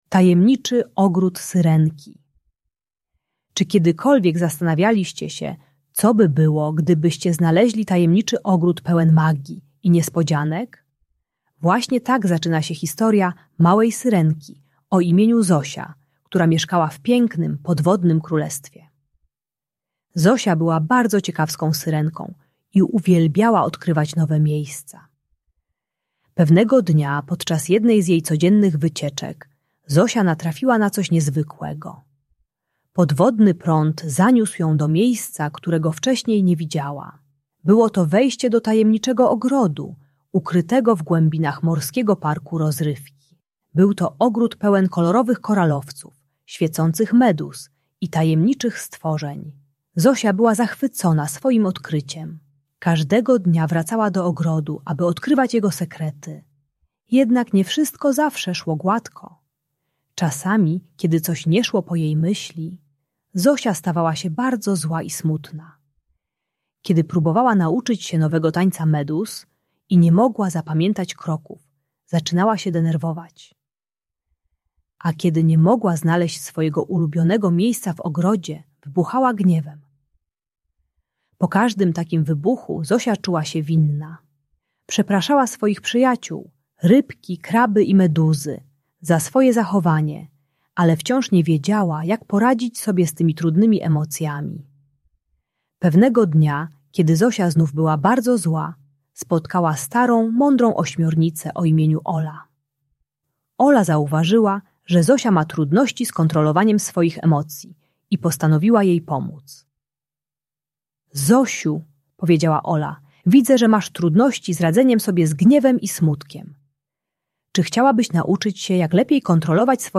Tajemniczy Ogród Syrenki - Bunt i wybuchy złości | Audiobajka